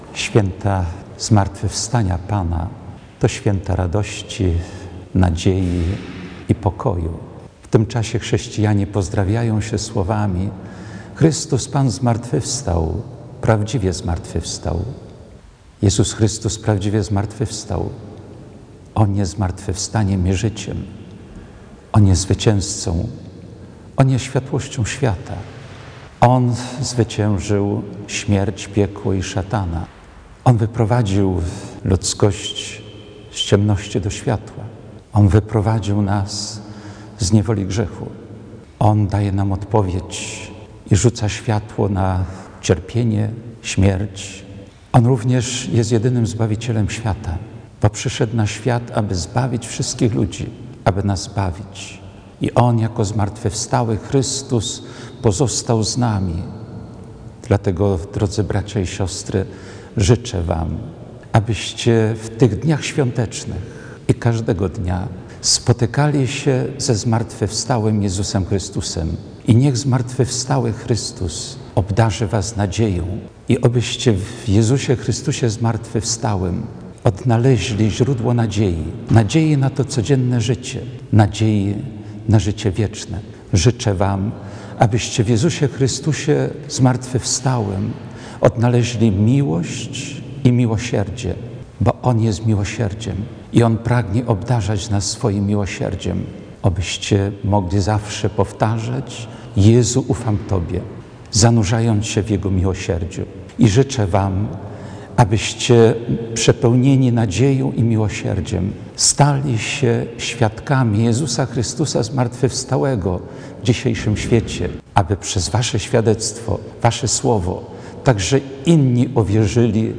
Orędzie Wielkanocne
– Święta zmartwychwstania Pana, to święta nadziei, miłości i pokoju – mówi w Wielkanocnym orędziu Biskup Diecezji Ełckiej ksiądz Jerzy Mazur.
oredzieBiskupa-całe.mp3